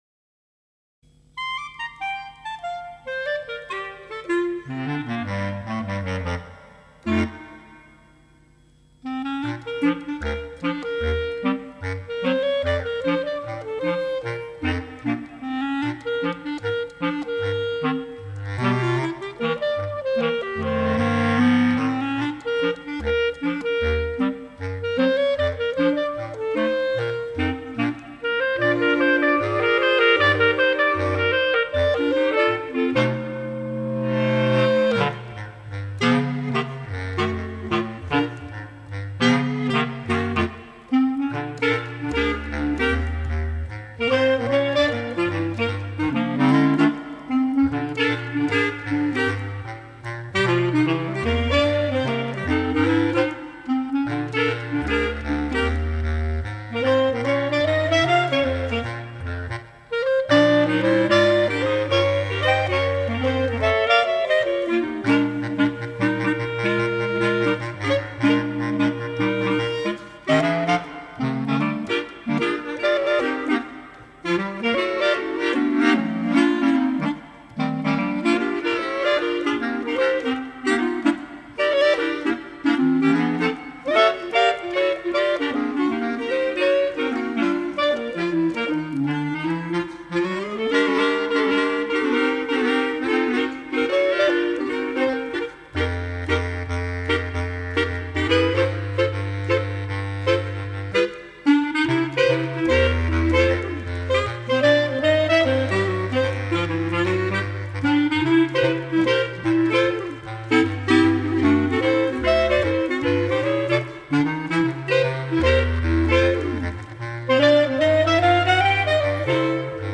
Bass Clarinet Lowest Note: E1